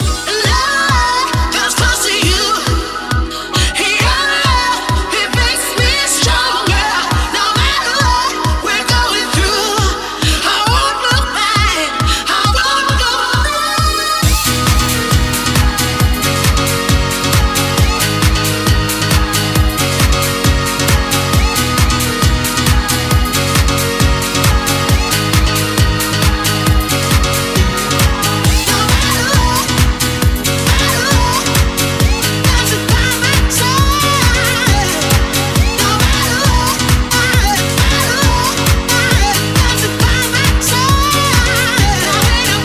Genere: deep, dance, edm, club, remix